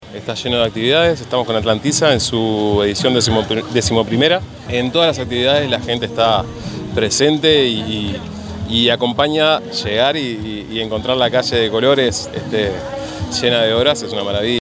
El Alcalde interino de la localidad, Luis Trujillo, afirmó que se acercó muchísimo público a participar: “Atlántida está lleno de actividades, en todas las actividades la gente está presente y acompaña. Llegar y encontrar la calle llena de colores, llena de obras, es una maravilla”.
alcalde_interino_de_la_localidad_luis_trujillo.mp3